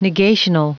Prononciation du mot negational en anglais (fichier audio)
Prononciation du mot : negational